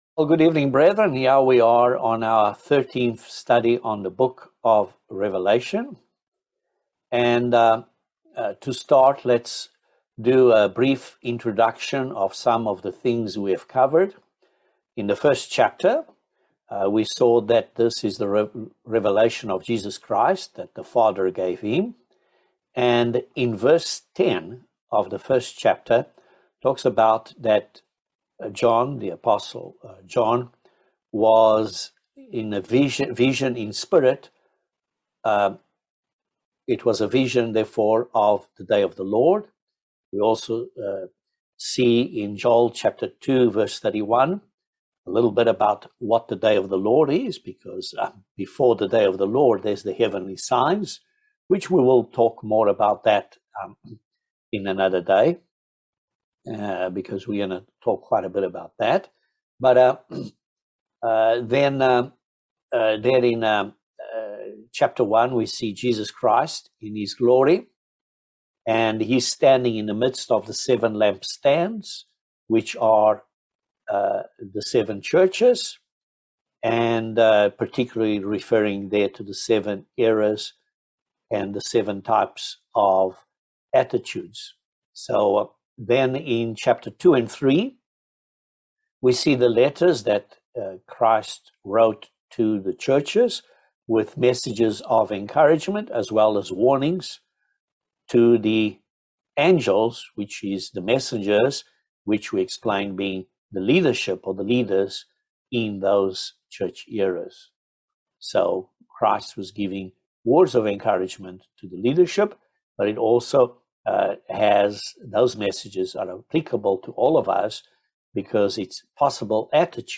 Bible Study No 13 of Revelation